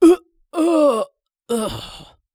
CK死亡1.wav
CK死亡1.wav 0:00.00 0:02.34 CK死亡1.wav WAV · 202 KB · 單聲道 (1ch) 下载文件 本站所有音效均采用 CC0 授权 ，可免费用于商业与个人项目，无需署名。
人声采集素材/男2刺客型/CK死亡1.wav